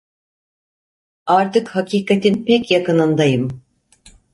Pronunciado como (IPA)
/pec/